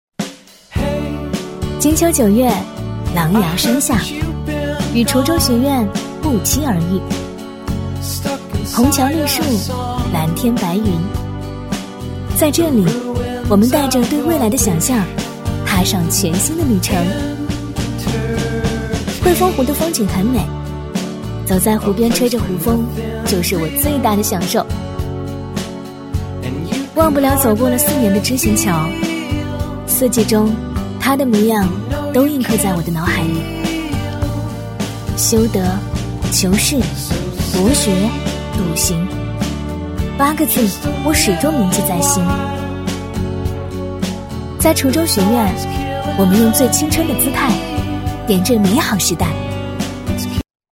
女B32-宣传-【学校 青春】
女B32-宣传-【学校 青春】.mp3